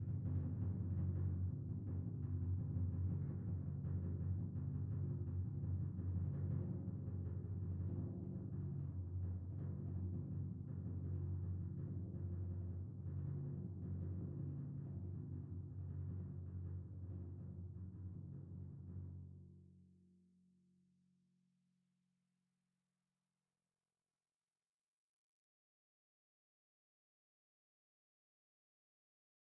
timpani2-hit-v3-rr1-sum.mp3